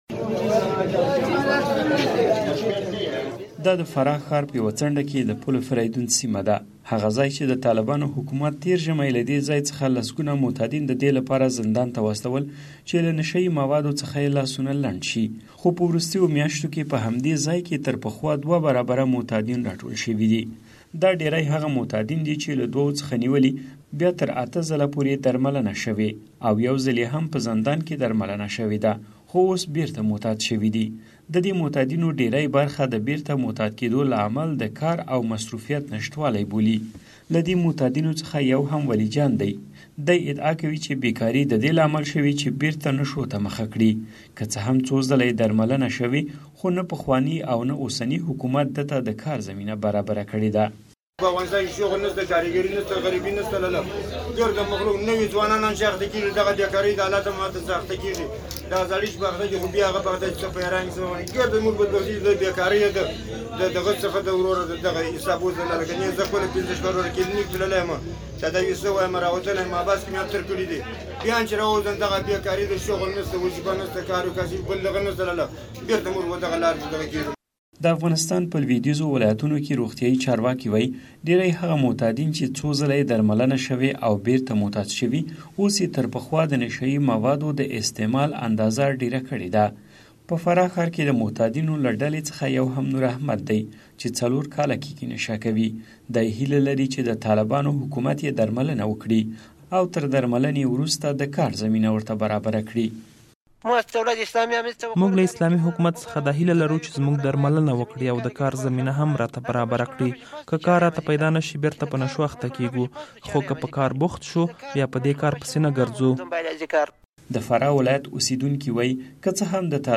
د فراه راپور